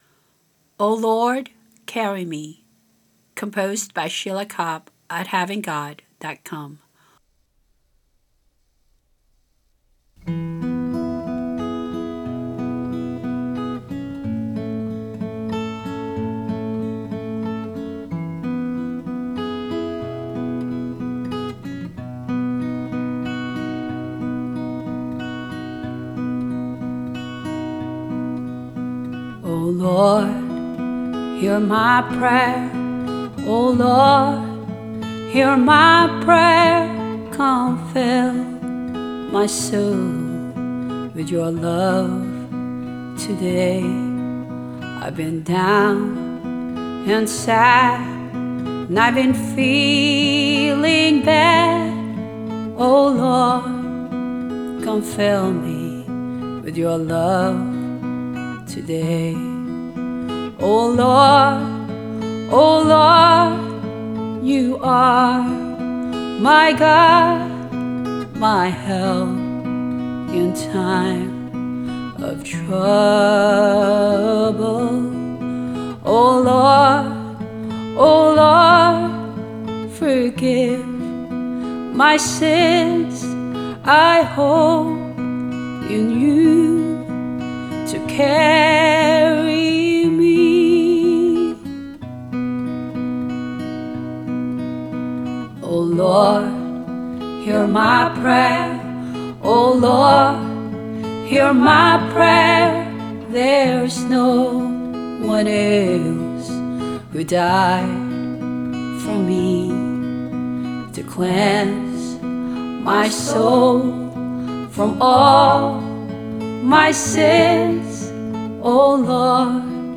Voices and guitar